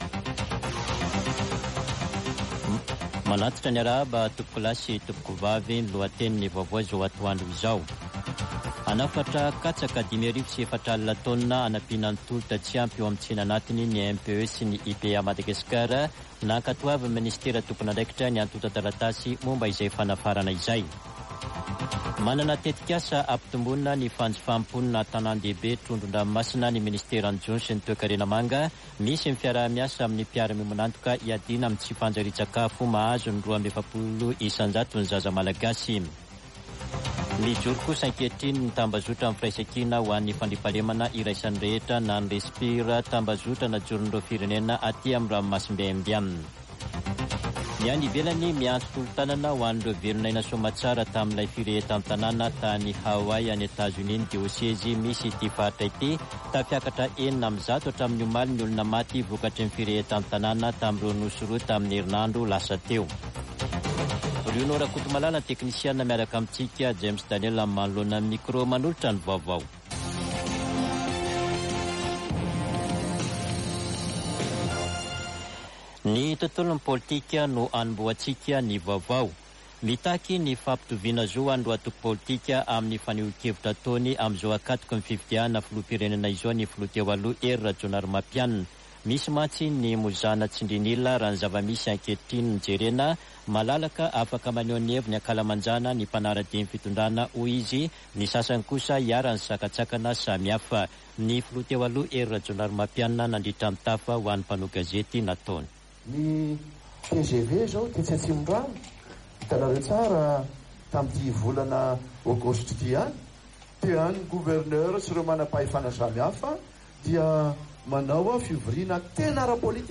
[Vaovao antoandro] Alakamisy 17 aogositra 2023